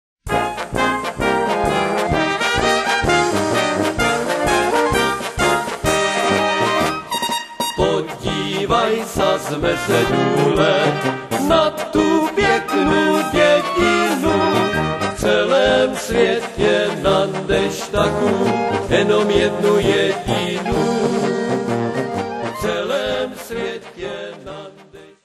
polka
valčík